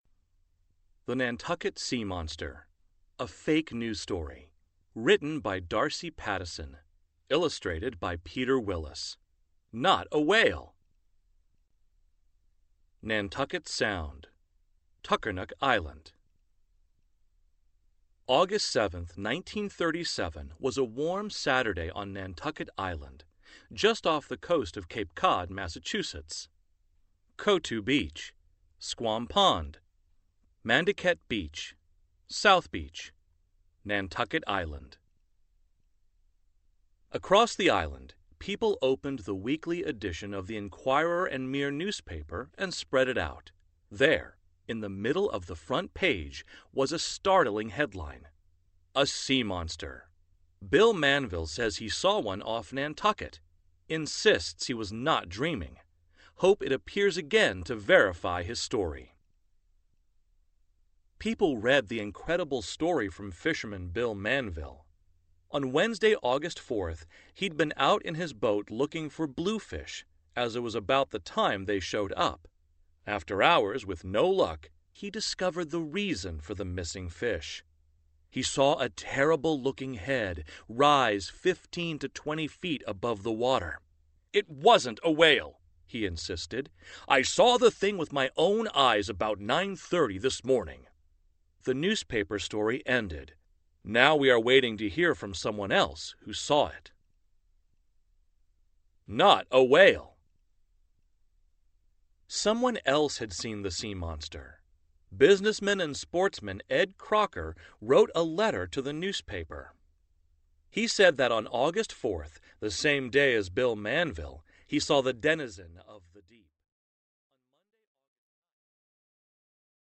Audiobook - The Nantucket Sea Monster